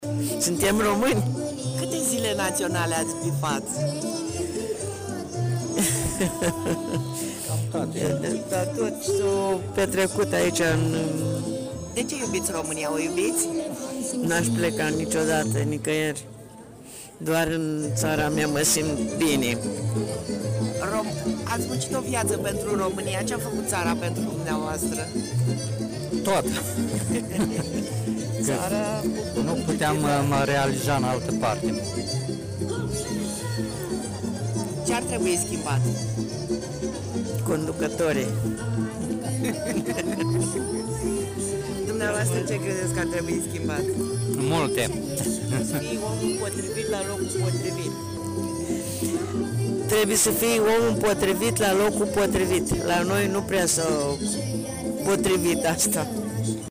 Oamenii au venit la parada și la ziua României.
Un alt cuplu mi-a spus că la capitolul guvernare ar trebui să fie omul potrivit la locul potrivit, dar la noi nu s-a cam potrivit asta.